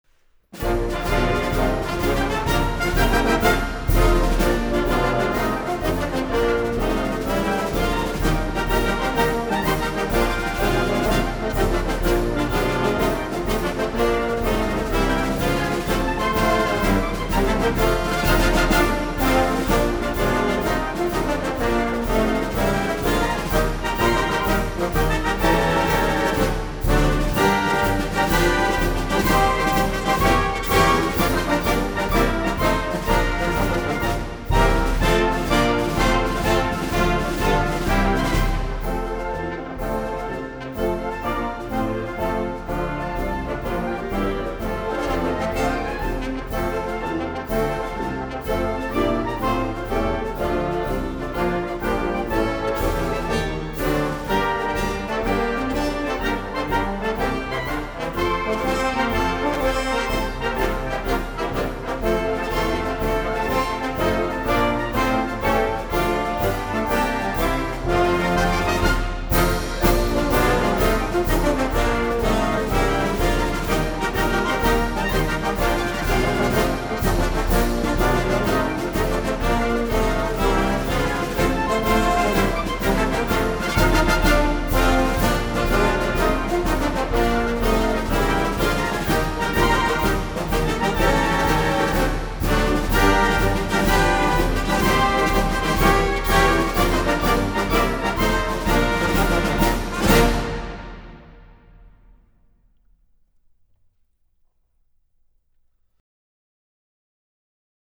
这是一张不吵的进行曲录音，它虽然没有办法换下您家中的1812炮声，